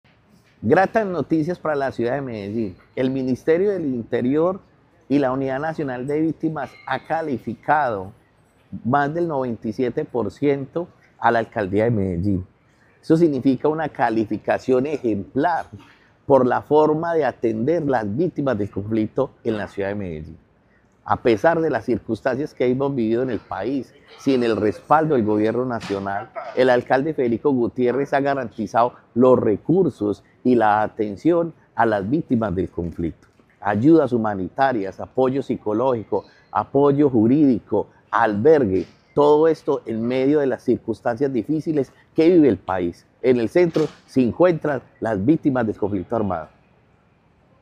Declaraciones del secretario de Paz y Derechos Humanos, Carlos Alberto Arcila Valencia
Declaraciones-del-secretario-de-Paz-y-Derechos-Humanos-Carlos-Alberto-Arcila-Valencia.mp3